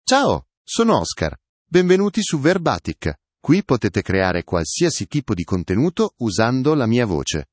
OscarMale Italian AI voice
Oscar is a male AI voice for Italian (Italy).
Voice sample
Listen to Oscar's male Italian voice.
Male
Oscar delivers clear pronunciation with authentic Italy Italian intonation, making your content sound professionally produced.